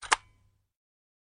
AK_Button.wav